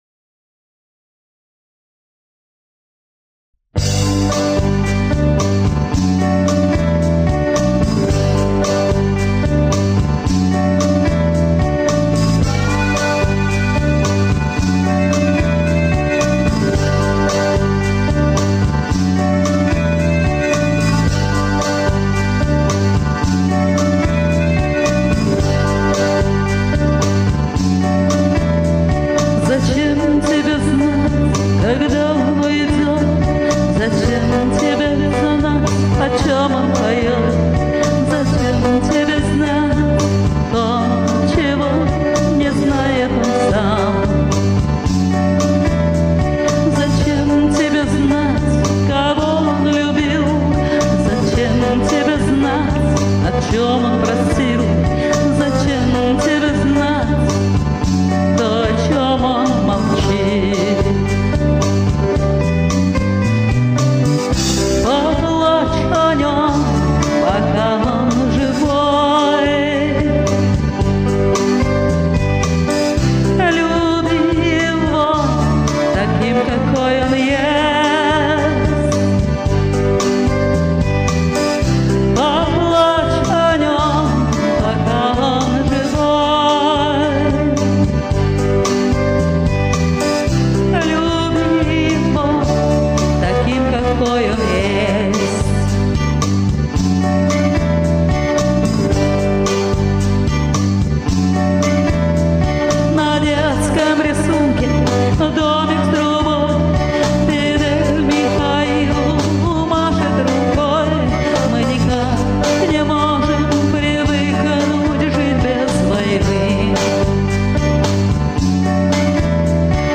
Мне нравится, когда женщины так рок поют!
Напряга не услышала!